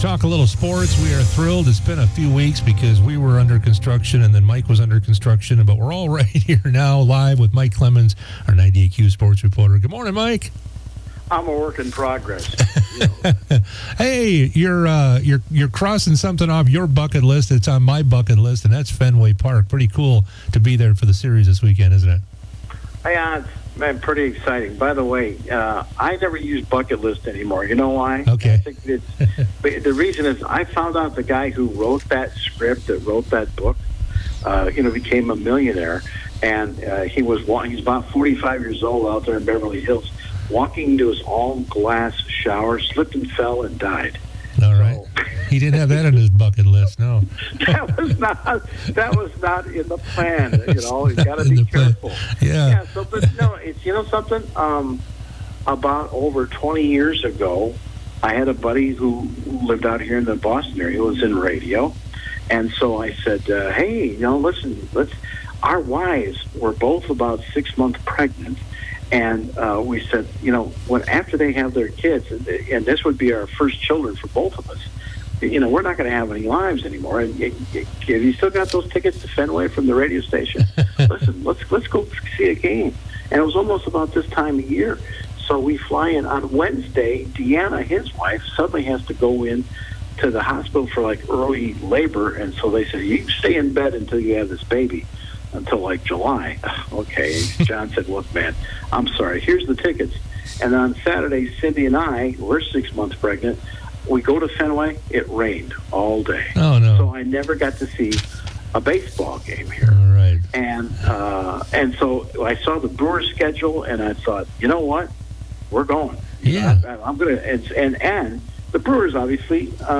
Interviews and special broadcasts from 98Q Country in Park Falls.
98q interviews